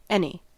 Ääntäminen
Vaihtoehtoiset kirjoitusmuodot (rikkinäinen englanti) eeny (vanhentunut) anie Synonyymit nondescript Ääntäminen US UK : IPA : /ˈæni/ IPA : /ˈɛn.i/ US : IPA : /ˈɛn.i/ pin-pen: IPA : /ˈɪni/ Ireland: IPA : /ˈæni/